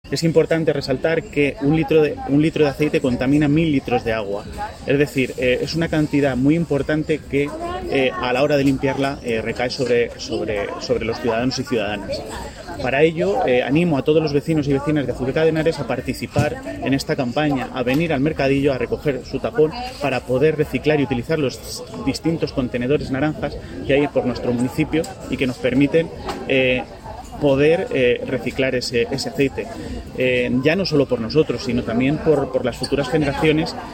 Declaraciones del alcalde sobre la campaña de reciclaje de aceite usado